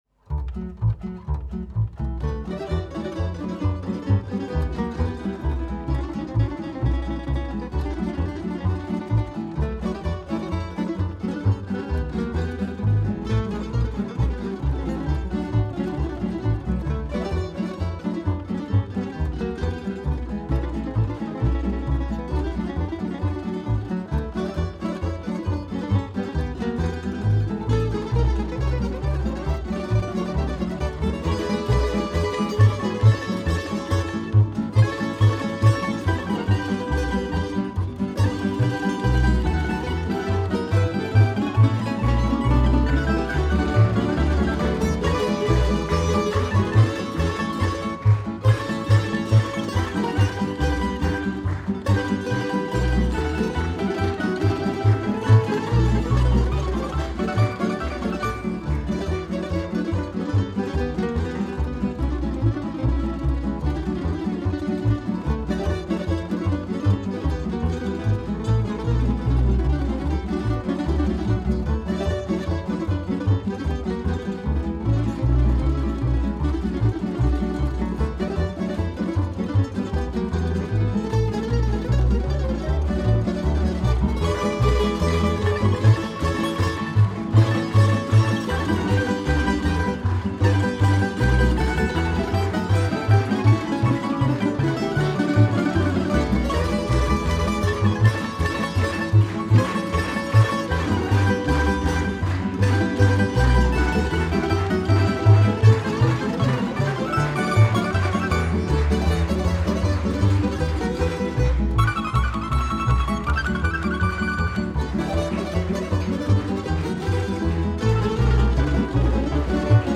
2014 Novi Sad - Festival "Bisernica Janike Balaža"
5 tracks - Live
Trad